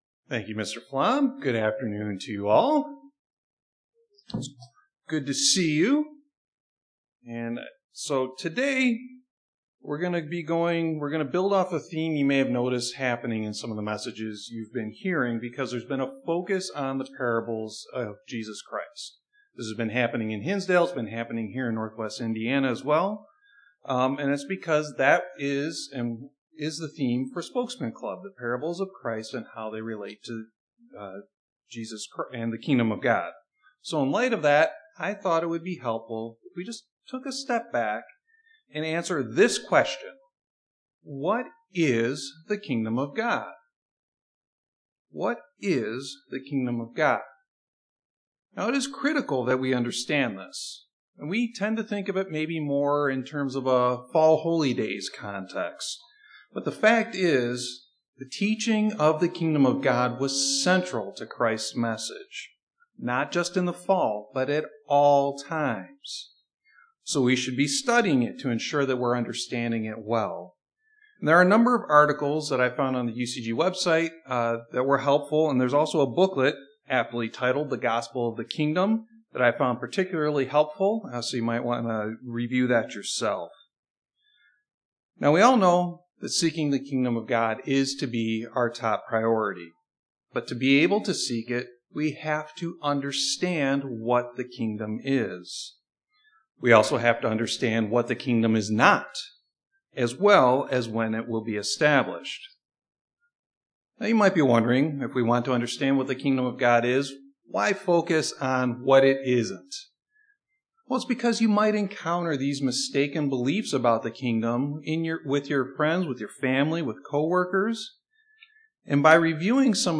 Sermons
Given in Northwest Indiana